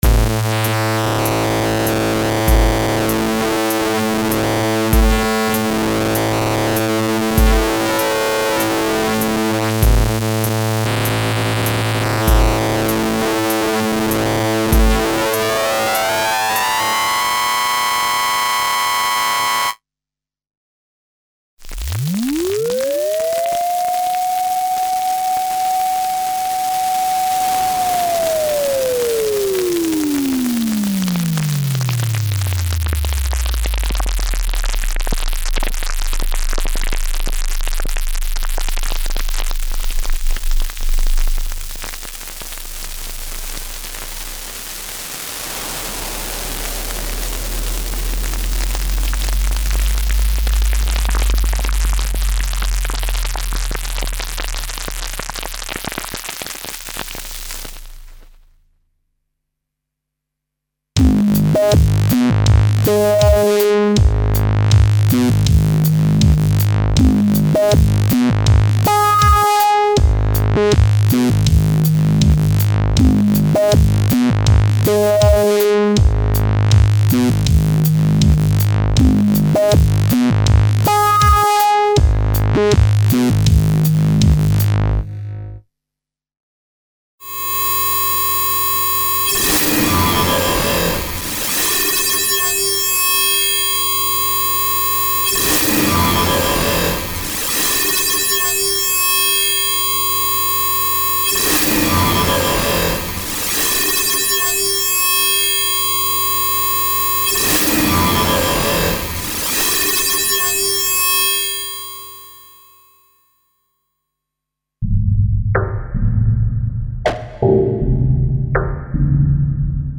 Oscillator sync and synth effect collection - razor sharp, absolute sonic extreme with no limit, created to take advantage of the special controller settings for internal DSP modulations (e.g. filter, pitch, shaper, wrap, distortion, etc.).
Info: All original K:Works sound programs use internal Kurzweil K2600 ROM samples exclusively, there are no external samples used.